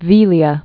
(vēlē-ə)